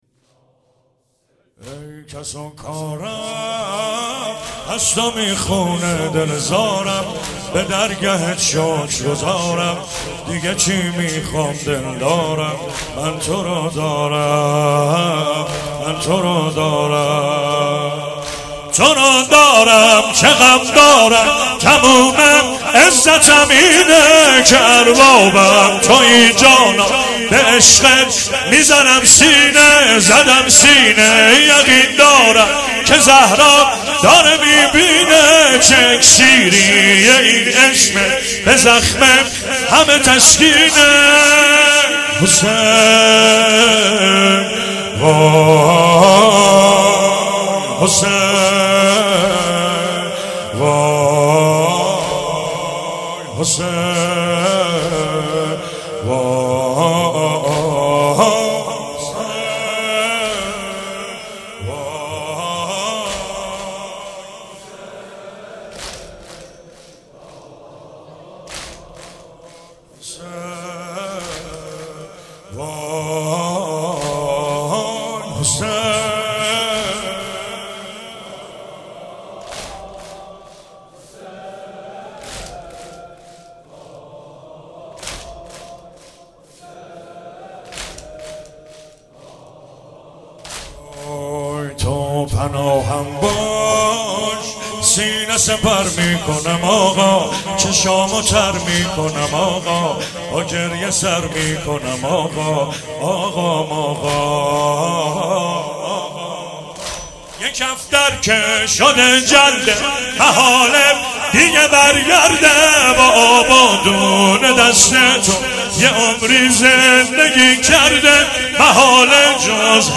سنگین- بی کس و آواره منم سرور و آقا غم تو